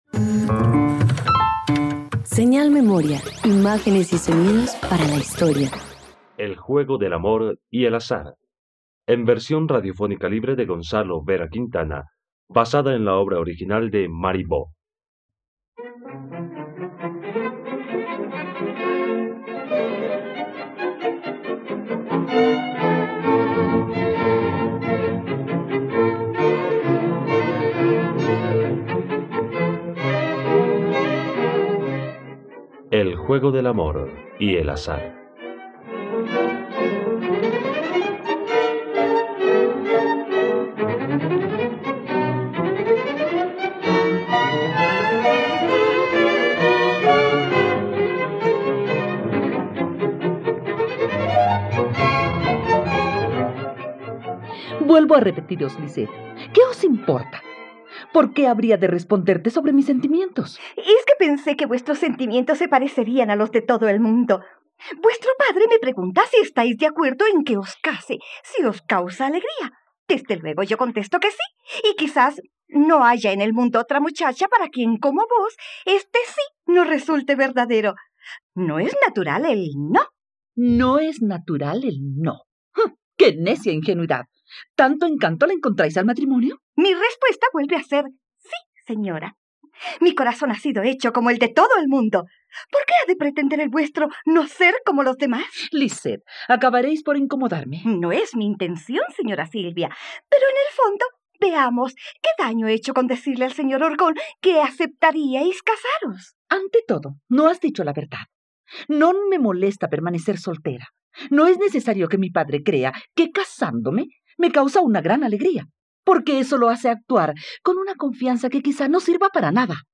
El juego del amor y el azar - Radioteatro dominical | RTVCPlay